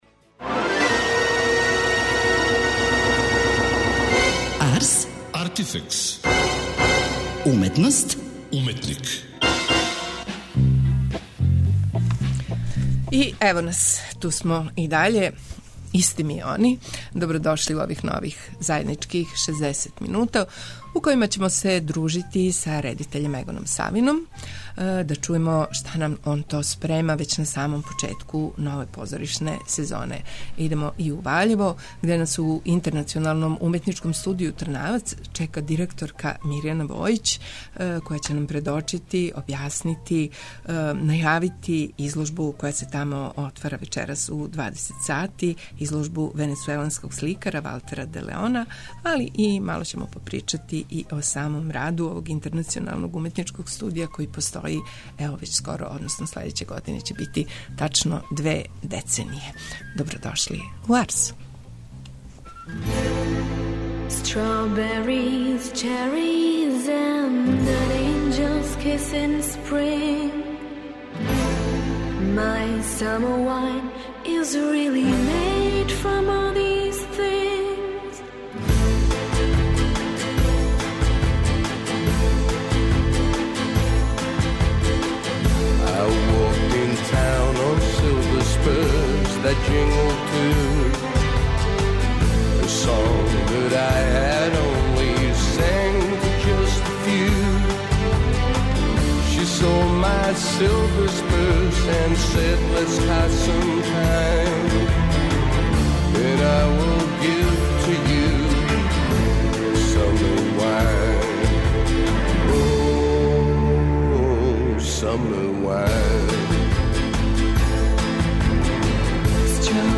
Редитељ Егон Савин открива нам своје планове за следећу позоришну сезону